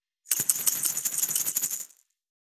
347岩塩を振る,調味料,
効果音厨房/台所/レストラン/kitchen